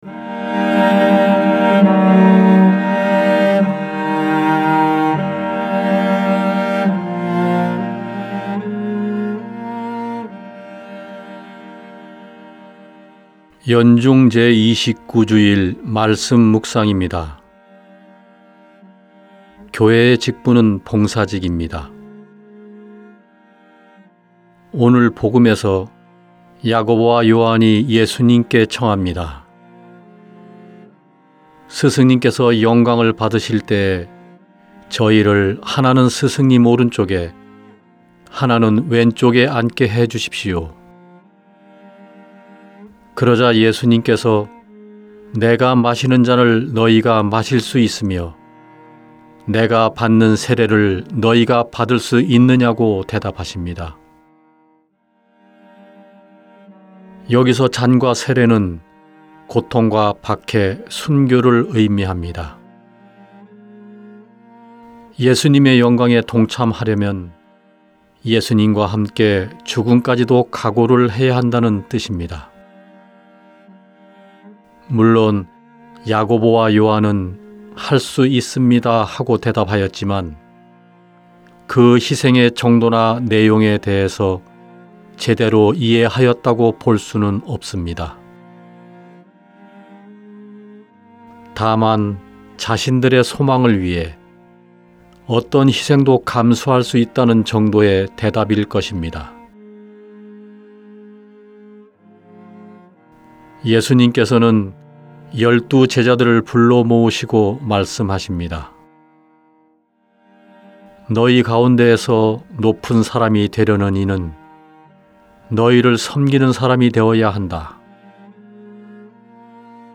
2021년 10월 17일 연중 제29주일 - 말씀묵상 듣기(☜파란색 글씨를 클릭하세요)